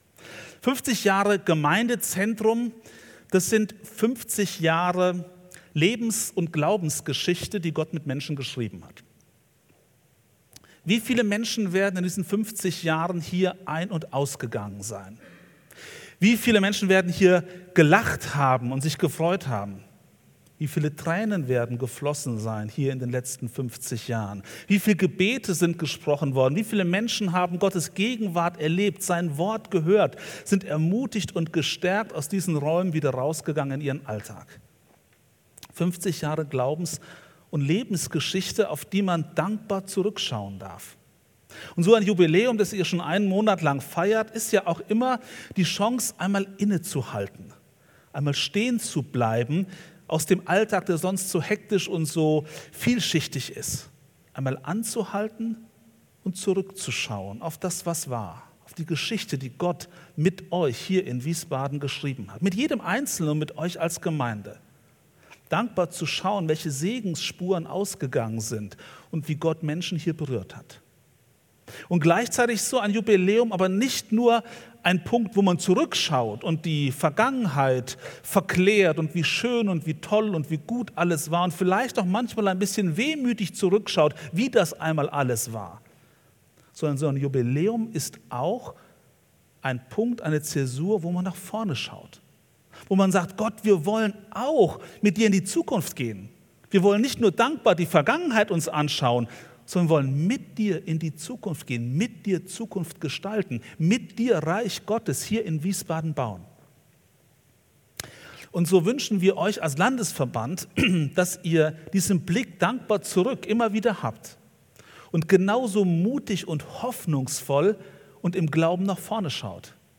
Predigt Glaube im Alltag - so unterschiedlich und doch so tief!